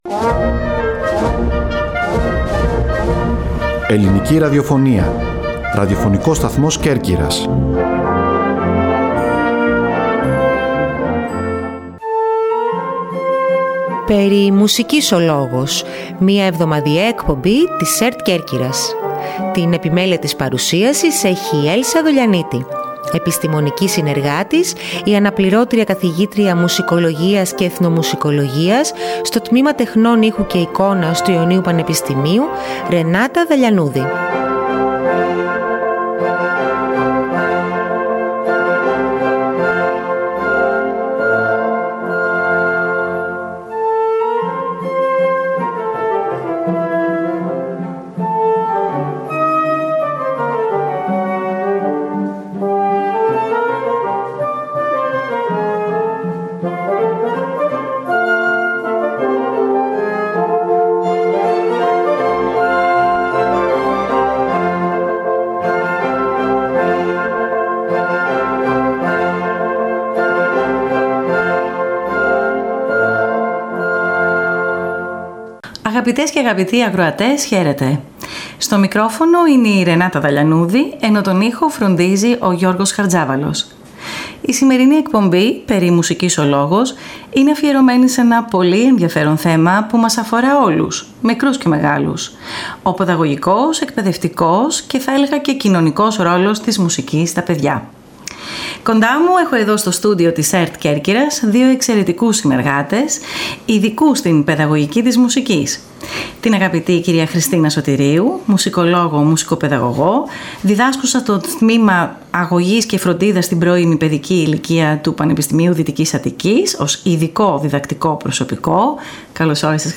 Καλεσμένοι στο studio της ΕΡΤ Κέρκυρας